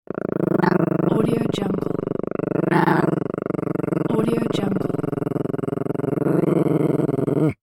دانلود افکت صدای غرغر حیوان خانگی
افکت صدای غرغر حیوان خانگی یک گزینه عالی برای هر پروژه ای است که به صداهای طبیعت و جنبه های دیگر مانند سگ، حیوان و پارس نیاز دارد.
Sample rate 16-Bit Stereo, 44.1 kHz